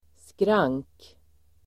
Ladda ner uttalet
skrank substantiv, railing , barrier Uttal: [skrang:k] Böjningar: skranket, skrank, skranken Synonymer: räcke Definition: räcke bar substantiv, skrank [juridik], domstol railing substantiv, skrank Förklaring: räcke